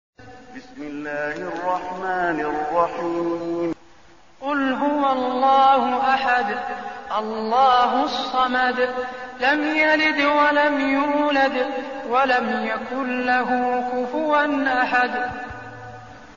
المكان: المسجد النبوي الإخلاص